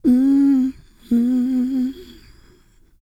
E-CROON P313.wav